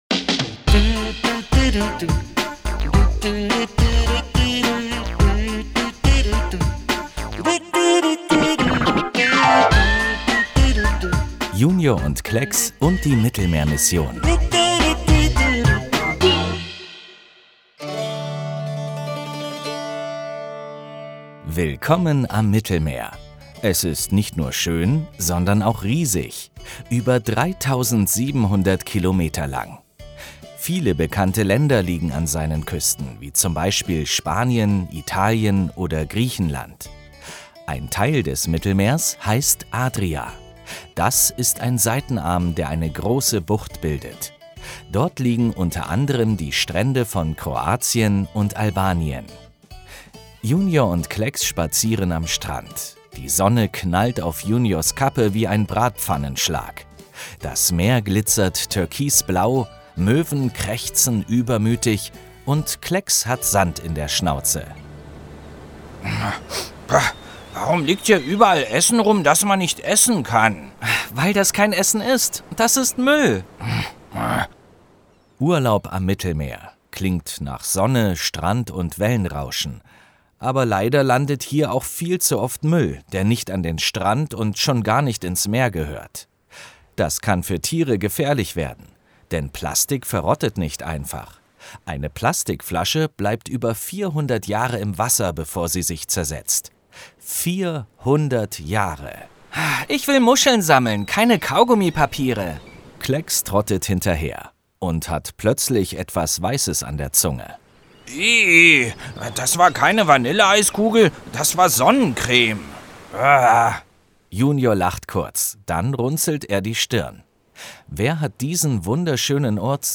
25 07 Hörspiel - JUNIOR Deutschland